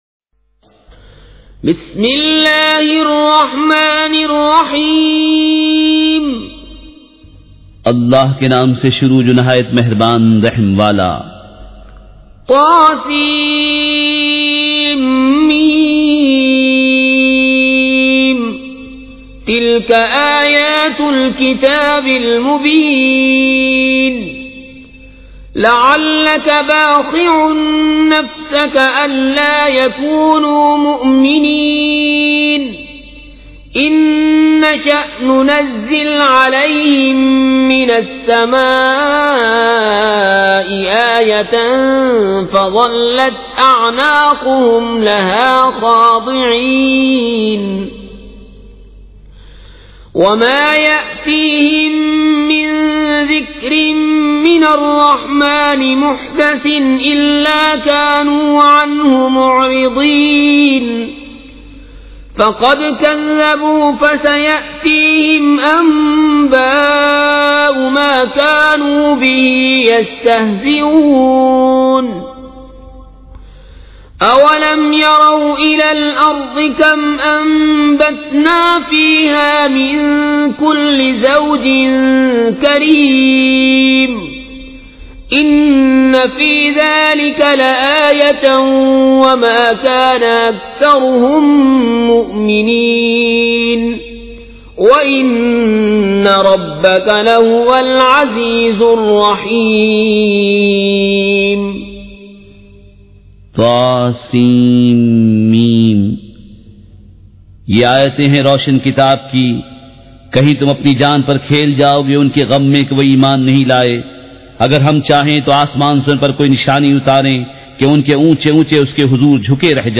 سورۃ الشعراء مع ترجمہ کنزالایمان ZiaeTaiba Audio میڈیا کی معلومات نام سورۃ الشعراء مع ترجمہ کنزالایمان موضوع تلاوت آواز دیگر زبان عربی کل نتائج 2058 قسم آڈیو ڈاؤن لوڈ MP 3 ڈاؤن لوڈ MP 4 متعلقہ تجویزوآراء